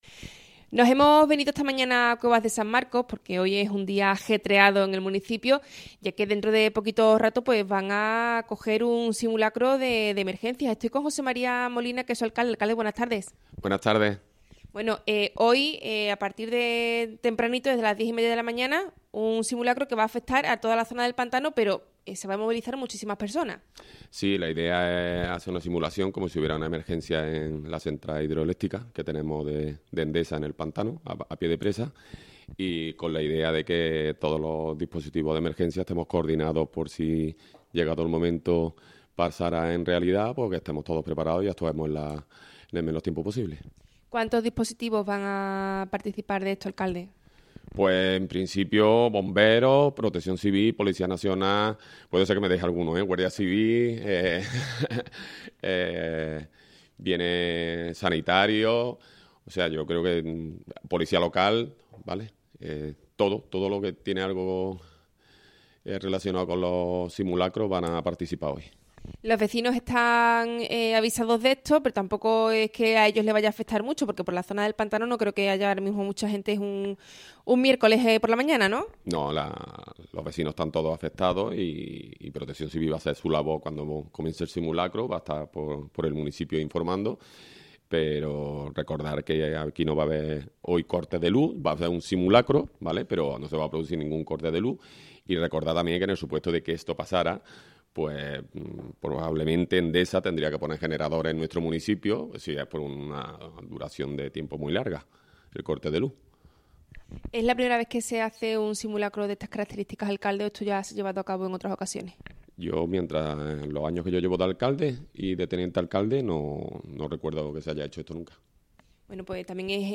Entrevista José María Molina. Alcalde de Cuevas de San Marcos.